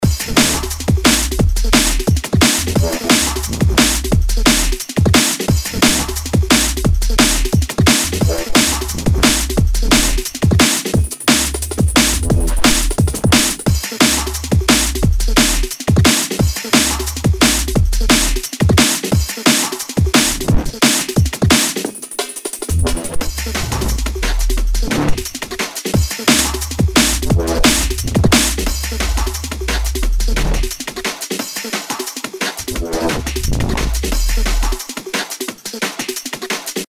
DNB
начал пробовать писать нейро фанк ......